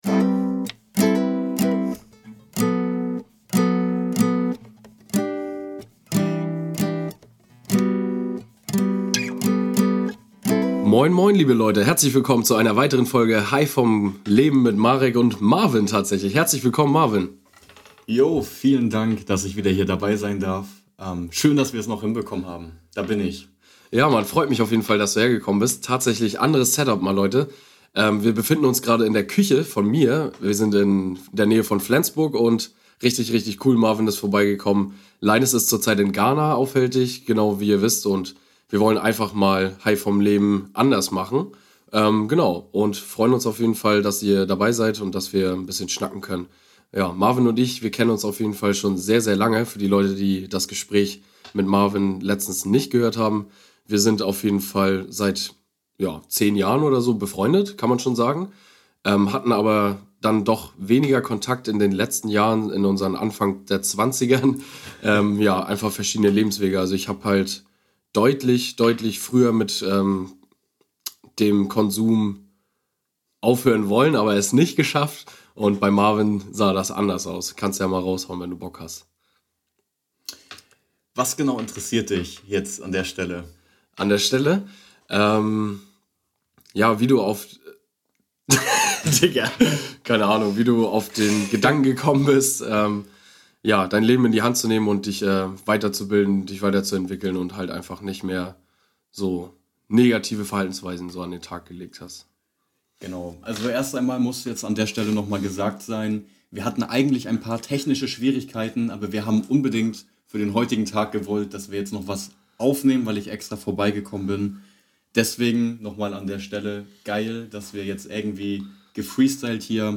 Aus der Küche direkt in euer Ohr! Wir haben uns zusammengesetzt und Sprechen über das Aufgeben unserer alten Lebens und Verhaltensweisen. Warum zieht man sich im Winter so zurück? Wie breche ich aus dem Kreislauf aus?